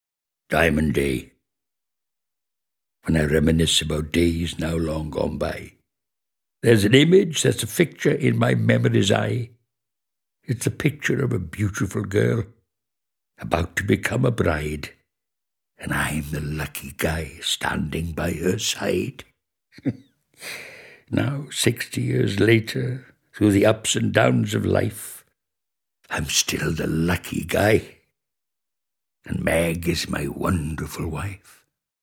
Click here to play poem read by Victor Spinetti